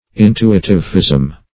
Meaning of intuitivism. intuitivism synonyms, pronunciation, spelling and more from Free Dictionary.
intuitivism.mp3